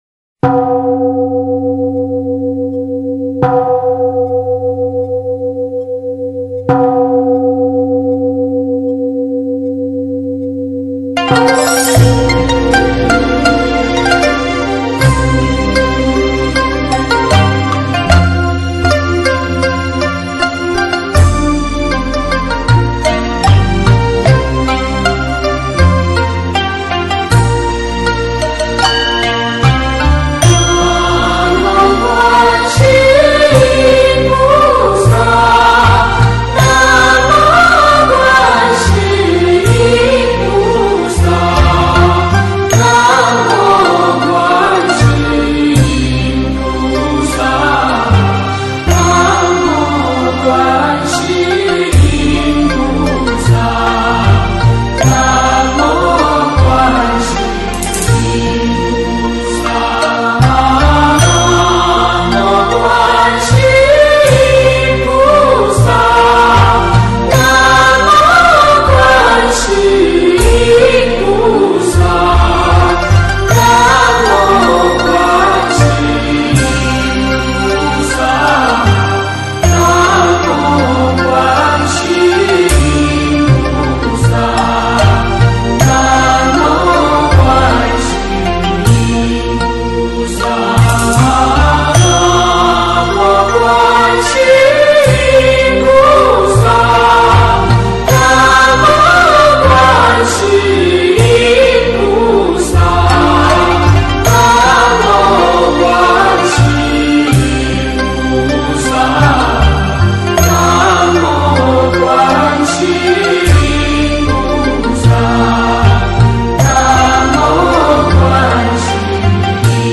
观世音菩萨圣号（合唱版） - 经文教念 - 心如菩提 - Powered by Discuz!